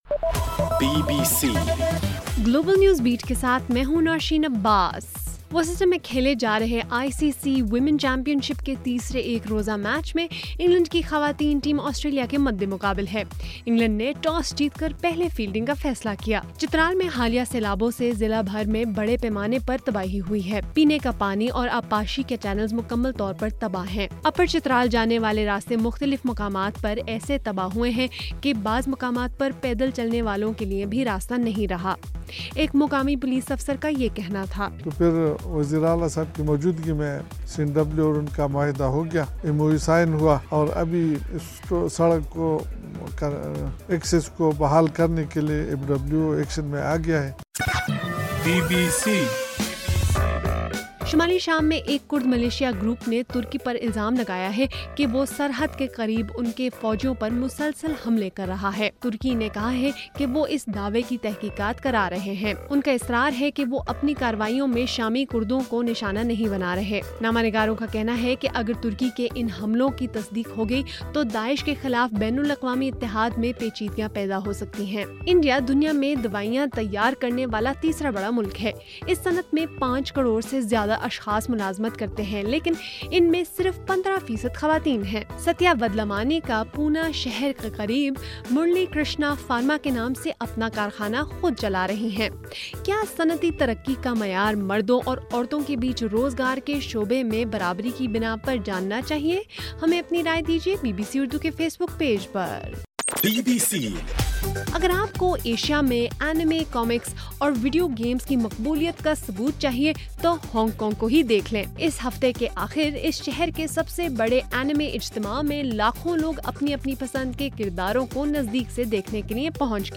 جولائی 27: رات 10 بجے کا گلوبل نیوز بیٹ بُلیٹن